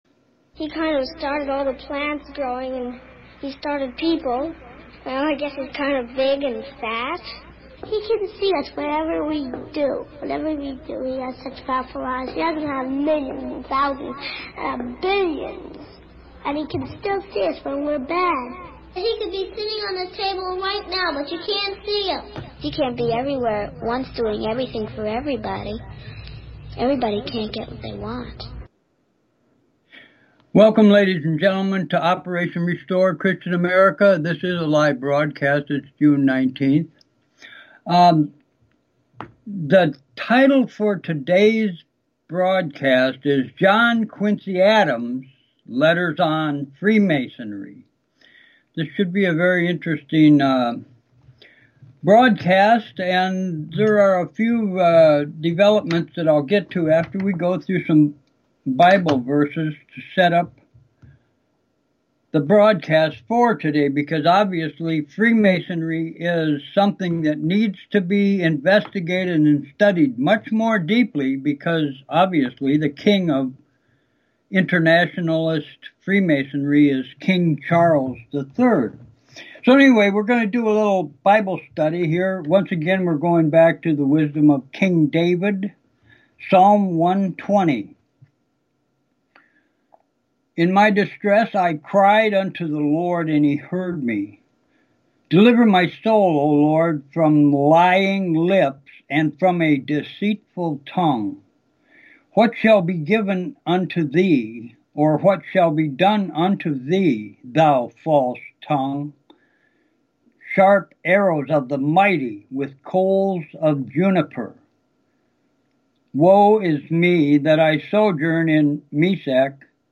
The broadcast today included several important matters; beginning with reading Psalm 120, then Mark 2:23-14-28, and finishing with Luke 3:12-14. In the Luke verses John the Baptist responds to a question from soldiers as to what their behavior is to be and he says harm no man and be content with your wages.Then moving on to announcements that we now receive live calls to give the opportunity to listeners for joining the talk show, announcing the new radio stations that have begun broadcasting our show, then on to the daunting subject of British Freemasonry.